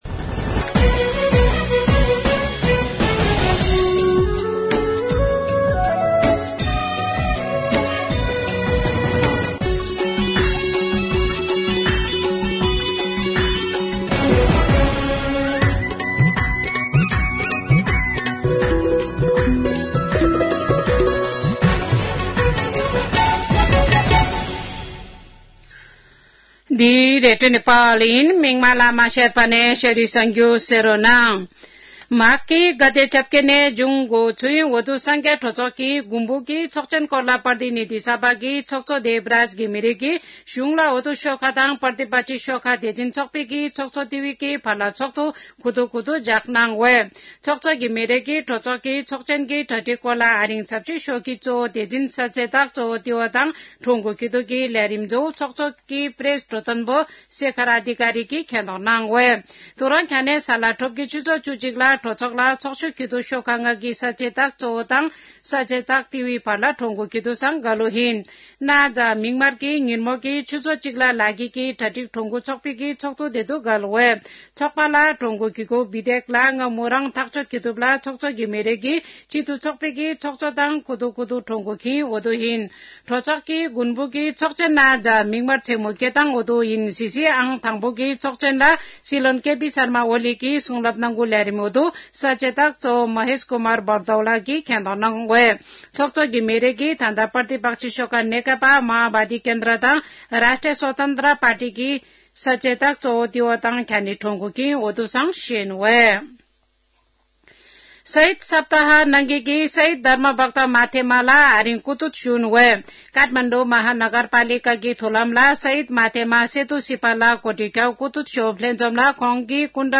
शेर्पा भाषाको समाचार : १४ माघ , २०८१
Sherpa-news-7.mp3